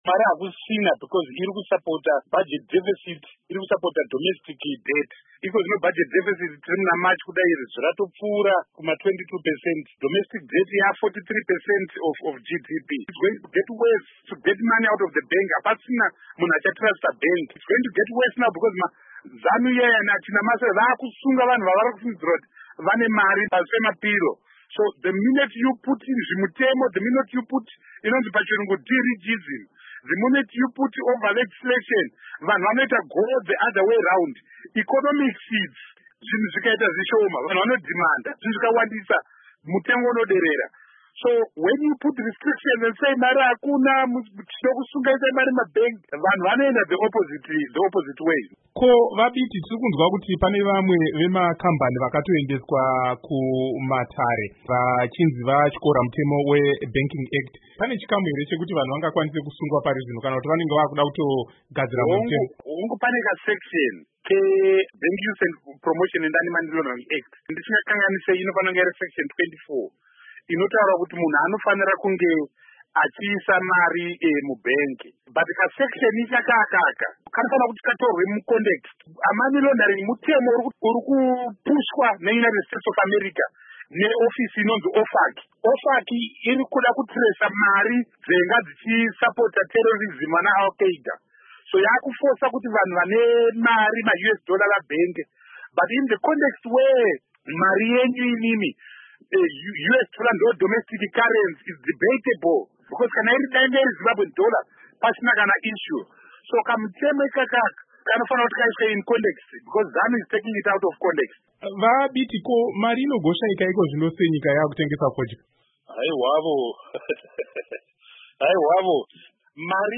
Hurukuro naVaTendai Biti